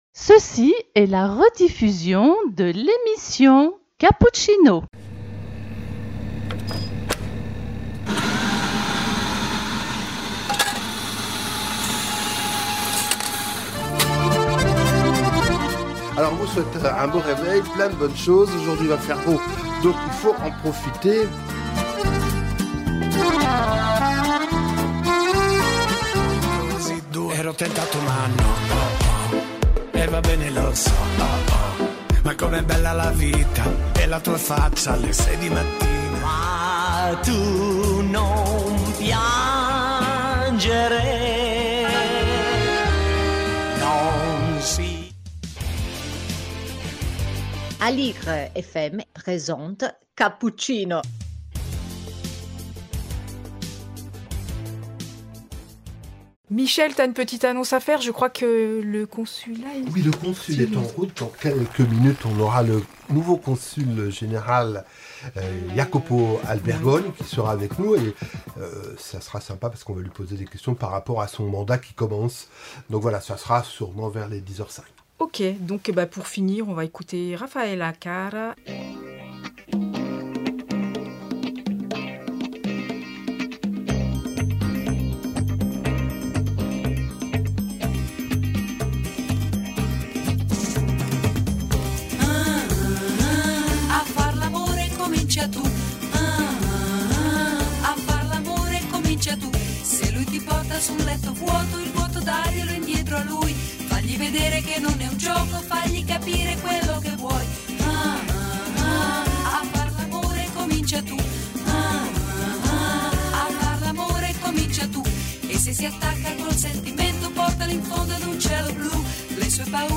Cappuccino # 8 décembre 2024 - invité Monsieur Jacopo Albergoni, Consul Général d'Italie à Paris